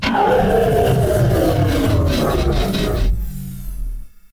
die1.ogg